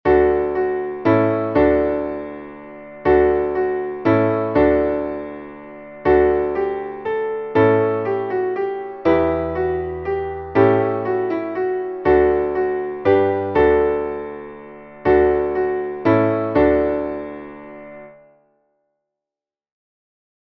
Traditionelles Kinder-/ Winter-/ Frühlings-/ Osterlied